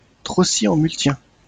Trocy-en-Multien (French pronunciation: [tʁɔsi ɑ̃ myltjɛ̃]